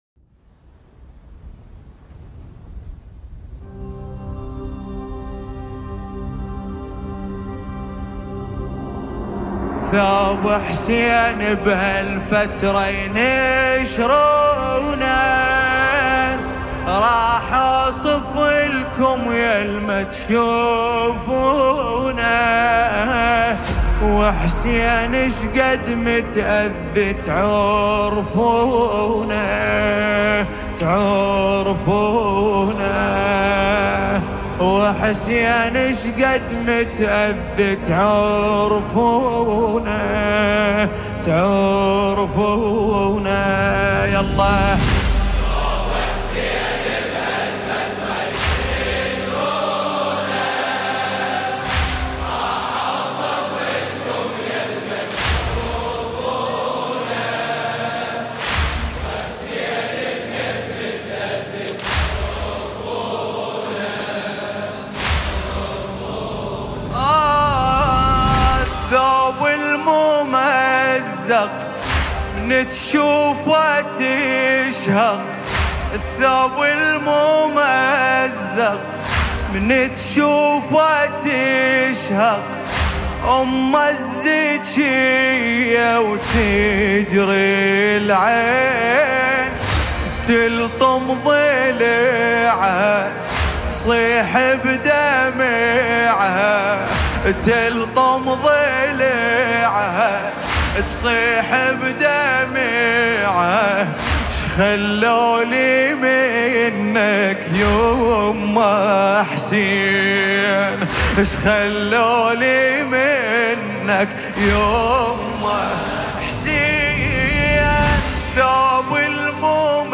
شهر محرم الحرام 1447 هـ